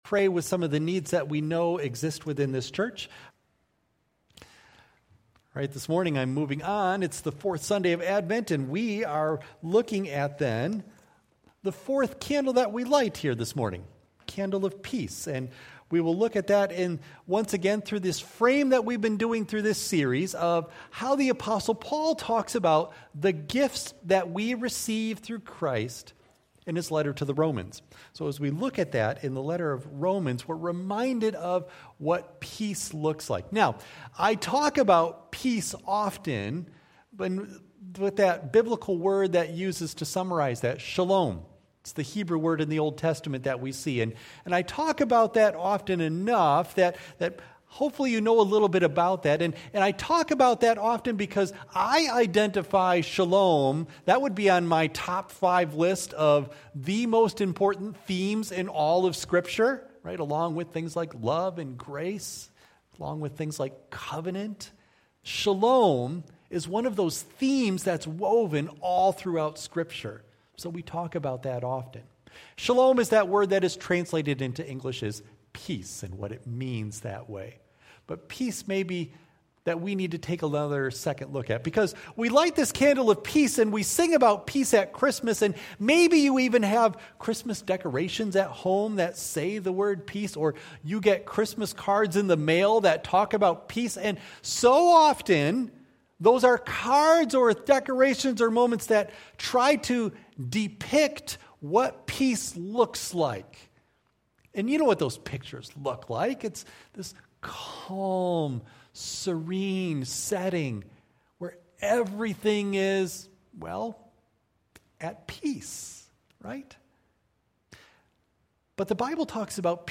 Audio of Message